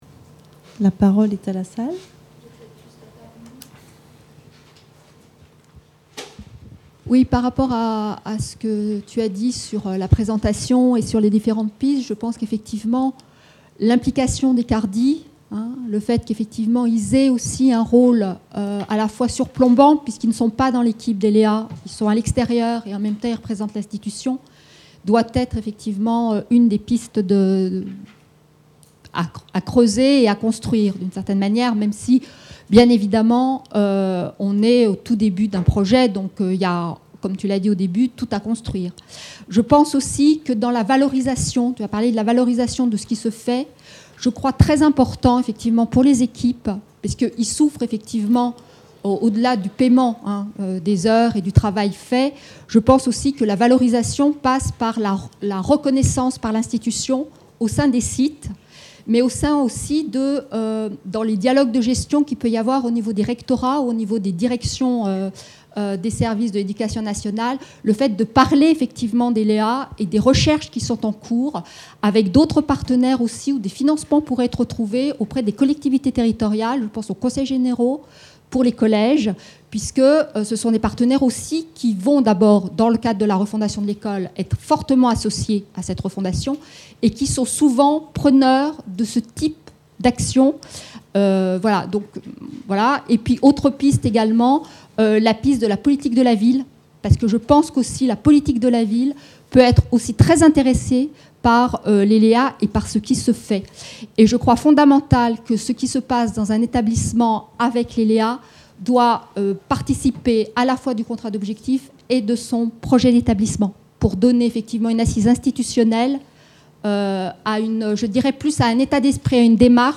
Questions dans les cadre du séminaire sur les outils des LéA, en octobre 2012.